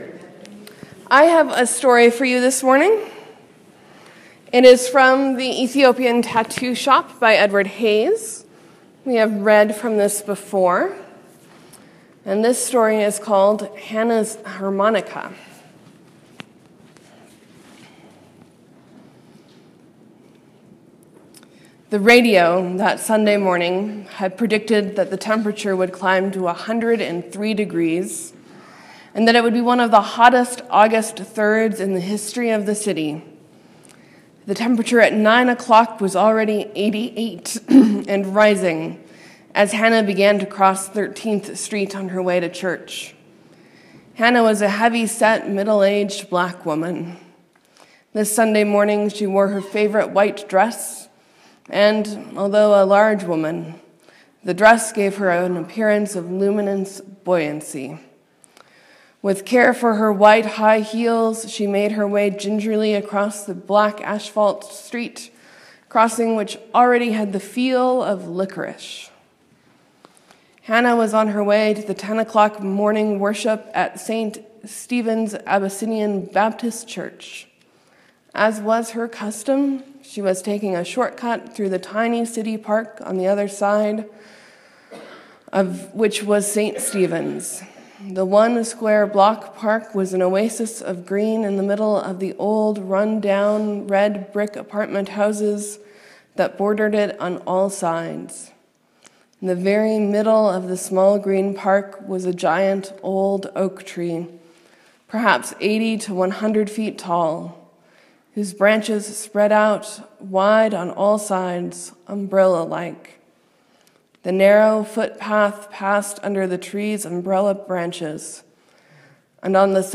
Sermon: Mary, Mary, and Salome went to do what their mother's taught them.